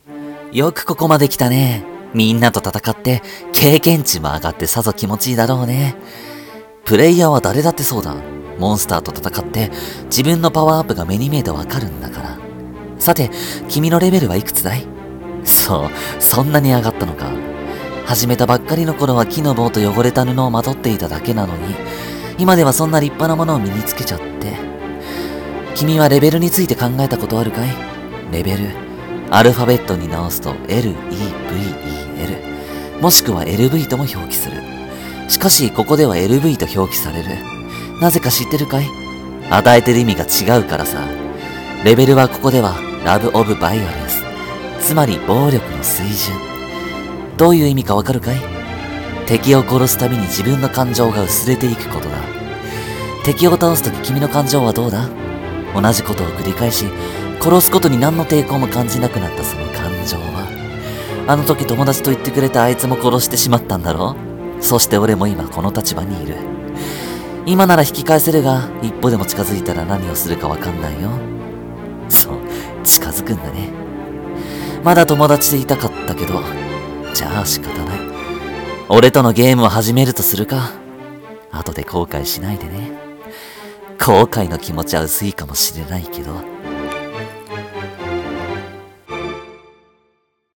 【声劇台本】GAME 🎮